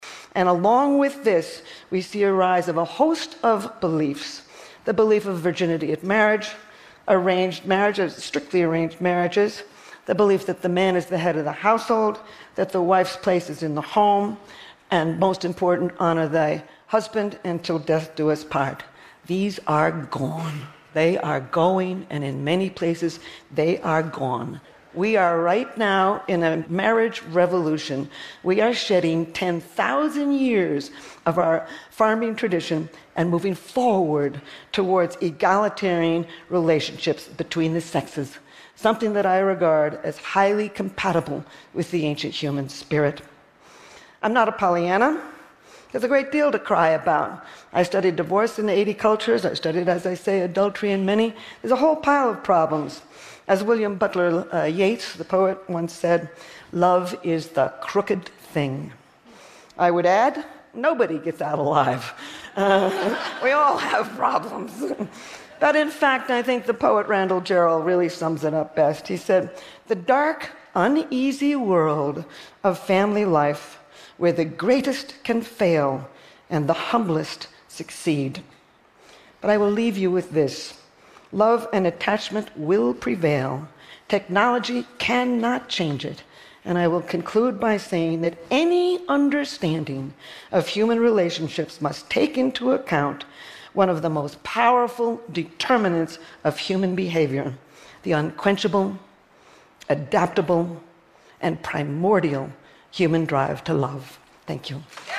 TED演讲:科技并没有改变爱 为什么?(8) 听力文件下载—在线英语听力室